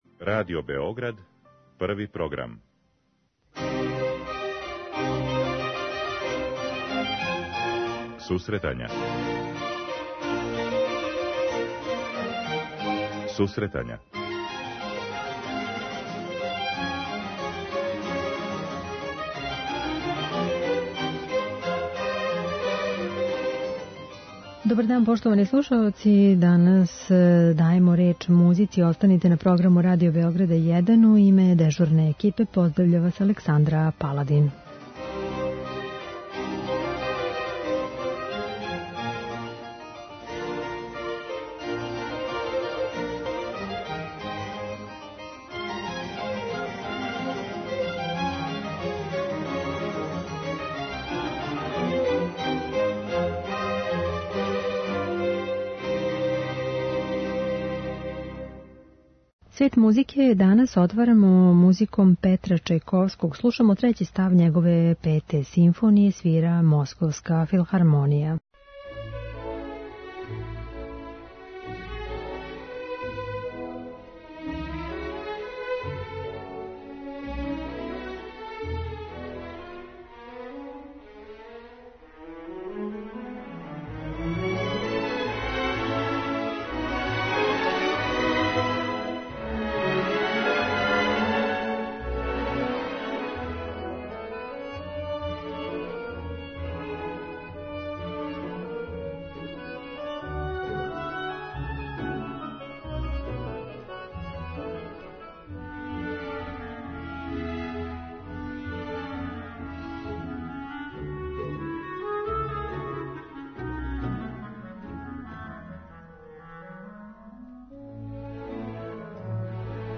преузми : 10.27 MB Сусретања Autor: Музичка редакција Емисија за оне који воле уметничку музику.